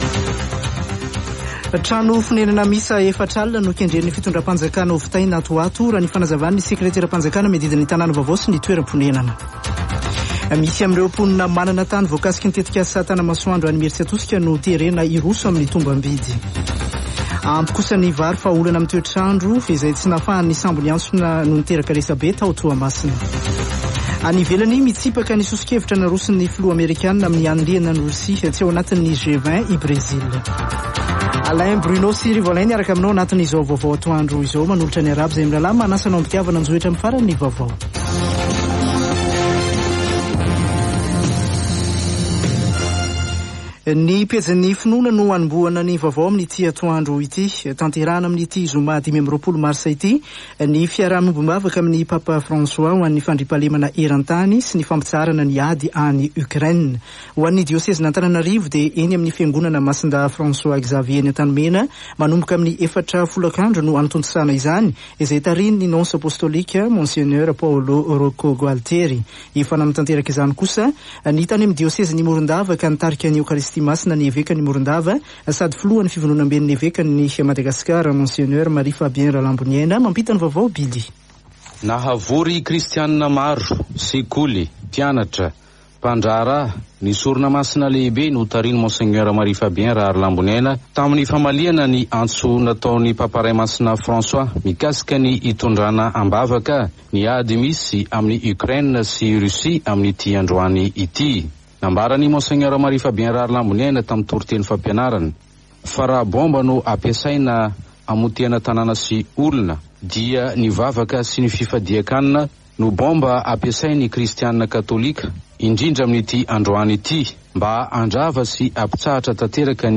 [Vaovao antoandro] Zoma 25 marsa 2022